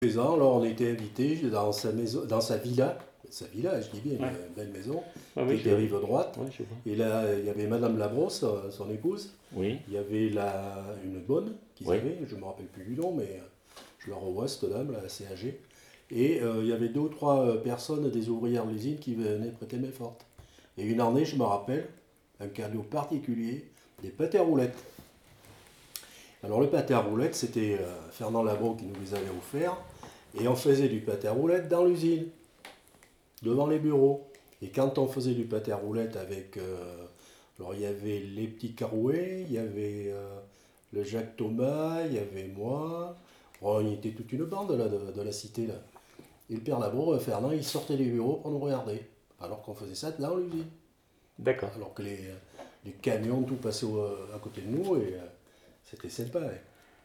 Mémoire de l'usine Labro, interview